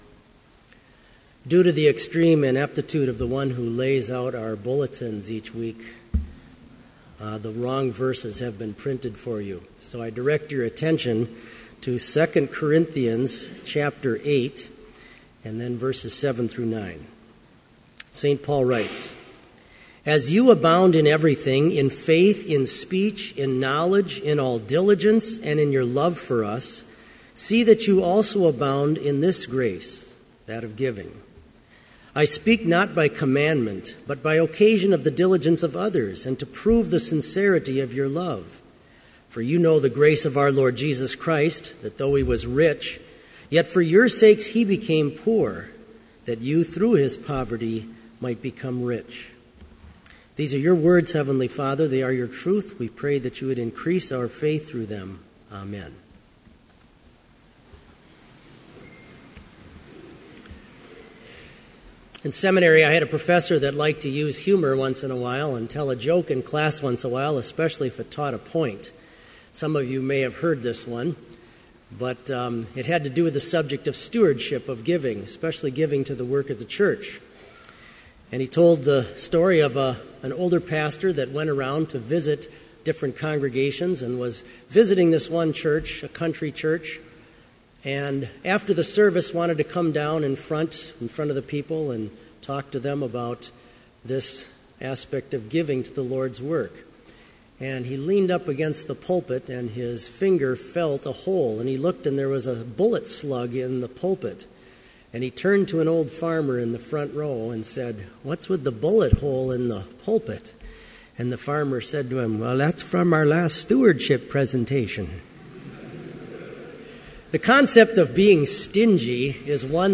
Complete Service
• Homily
This Chapel Service was held in Trinity Chapel at Bethany Lutheran College on Thursday, September 18, 2014, at 10 a.m. Page and hymn numbers are from the Evangelical Lutheran Hymnary.